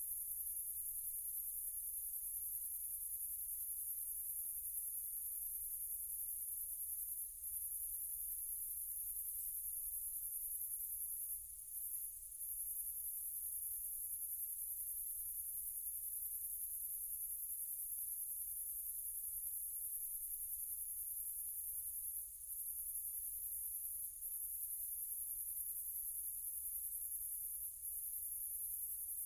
insectnight_3.ogg